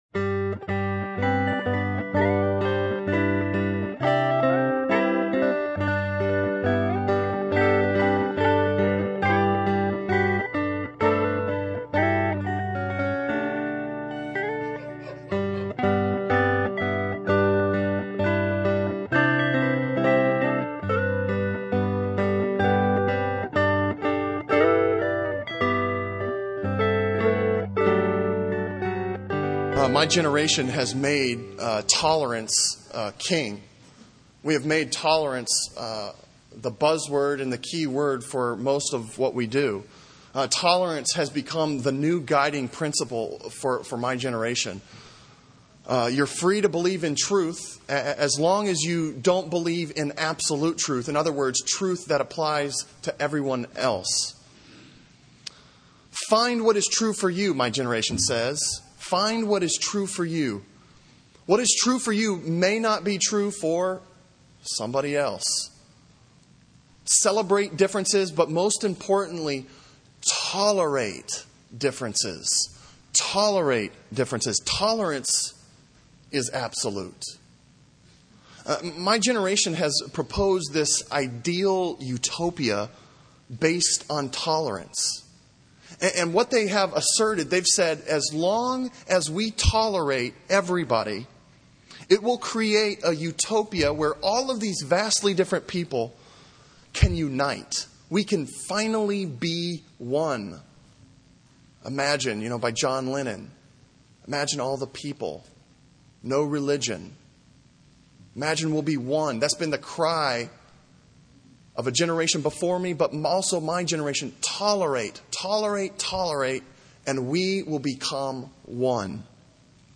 Sermon on Ephesians 2:11-22 from September 30